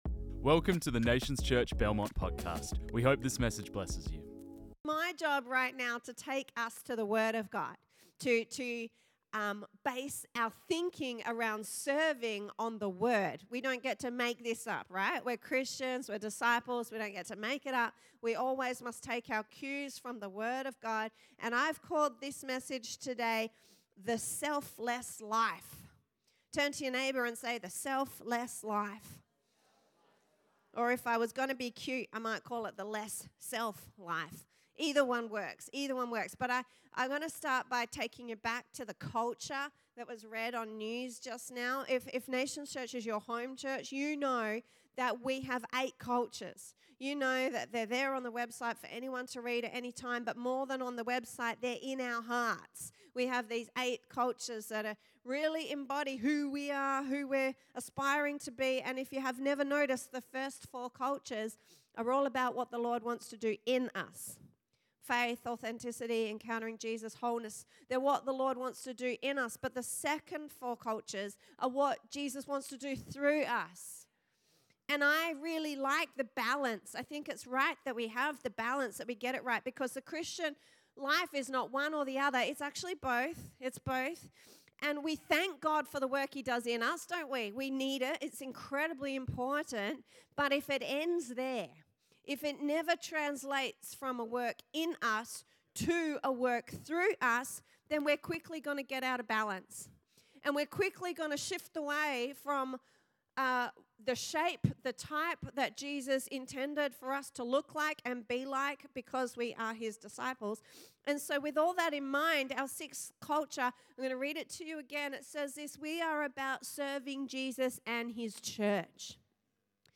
This message was preached on 1 October 2023.